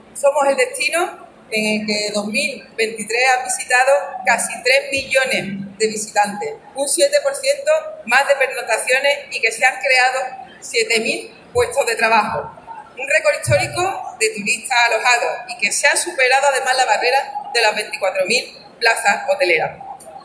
La cifra se duplica –hasta los 5,8- si se incluyen todos los tipos de alojamiento La Diputación de Cádiz ha celebrado este jueves su Día de la Provincia en Fitur con un acto en el escenario central del pabellón 5 de Ifema, en Madrid -el espacio que acoge a Andalucía-. Durante su intervención institucional, la presidenta de la Diputación, Almudena Martínez , ha informado sobre los datos del balance del sector hotelero durante el pasado año en la provincia.